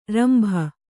♪ ranbha